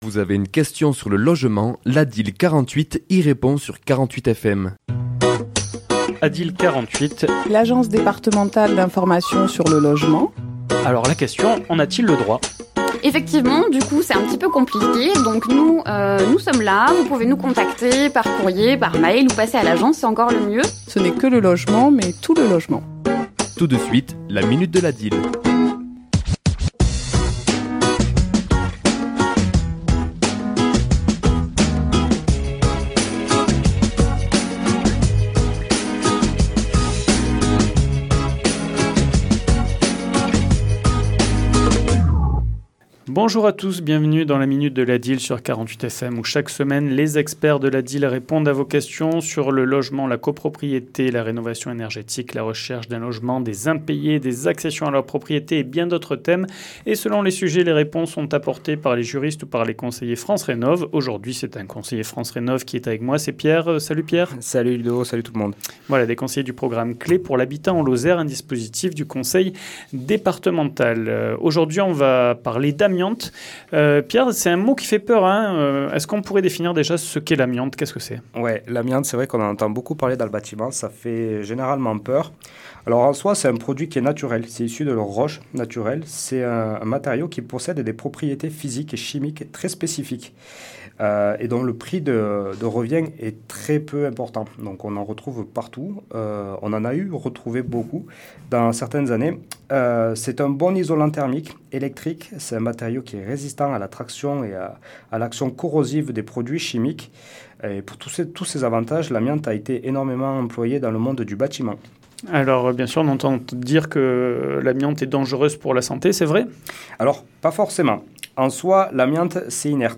Chronique diffusée le mardi 3 février à 11h et 17h10